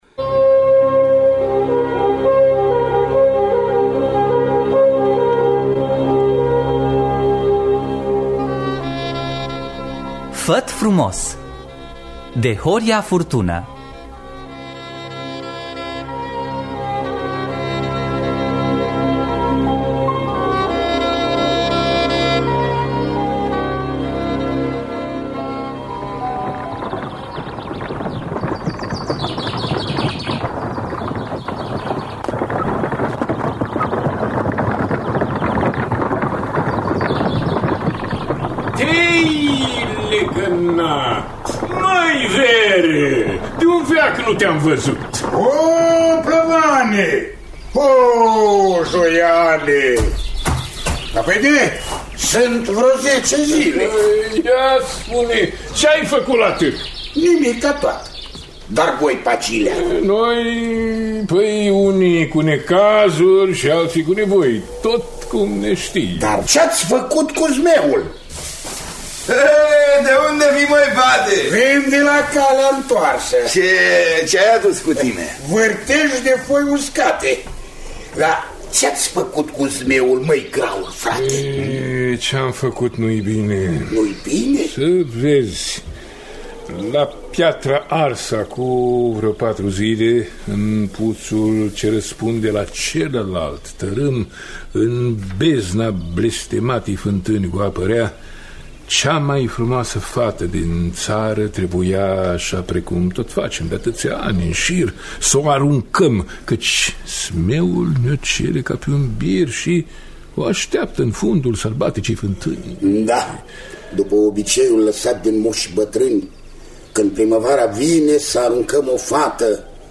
Făt Frumos de Horia Furtună – Teatru Radiofonic Online
Adaptarea radiofonică